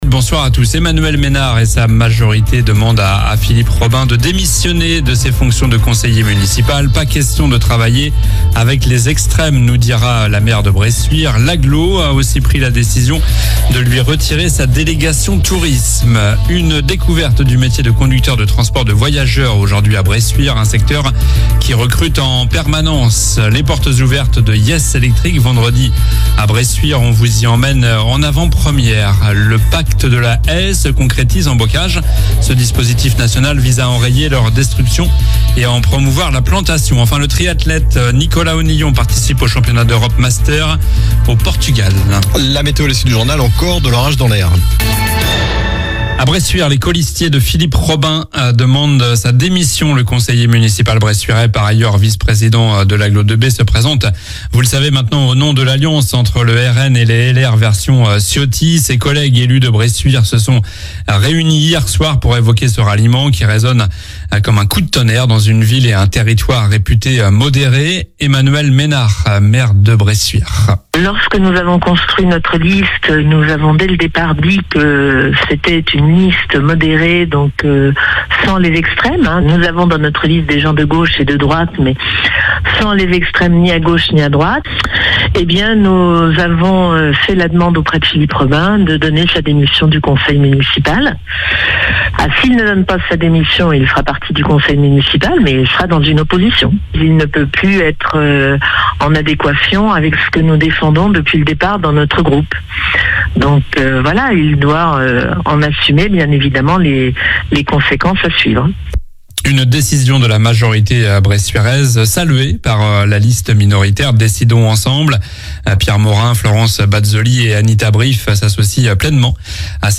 Journal du mardi 18 juin (soir)